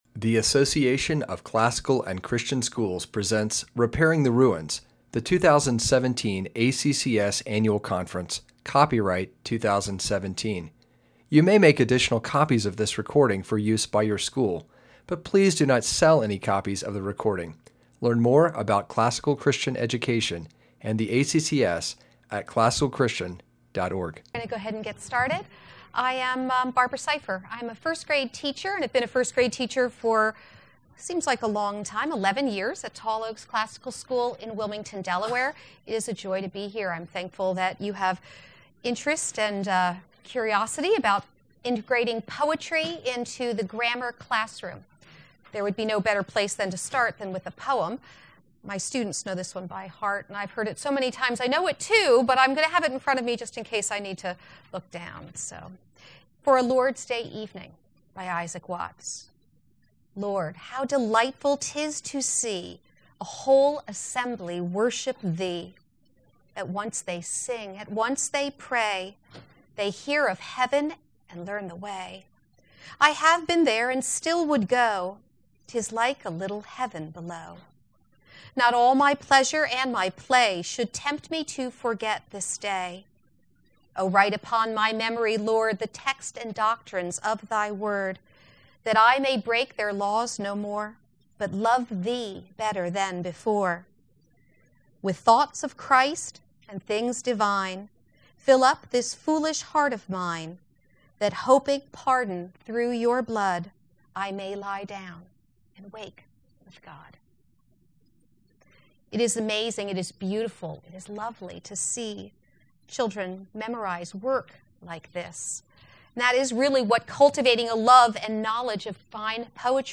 2017 Workshop Talk | 0:54:45 | K-6, Literature